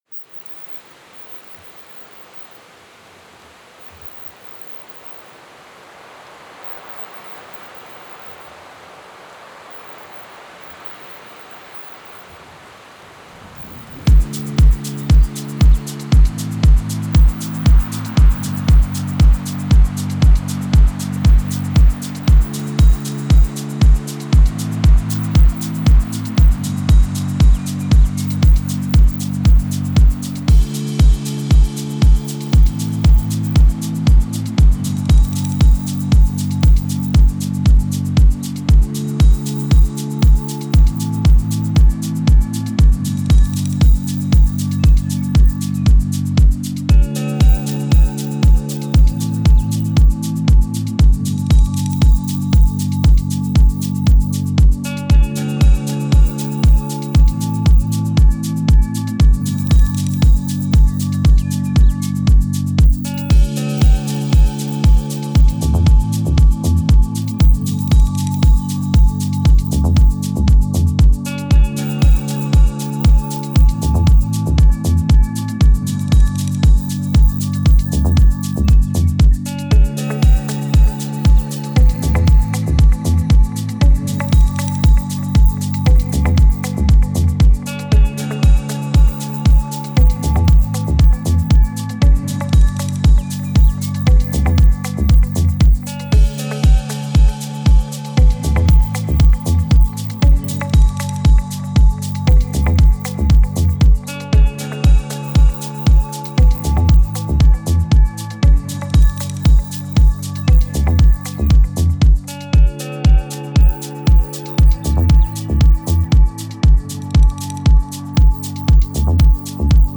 • Lágy háttérzene és finoman elrejtett szubliminális üzenetek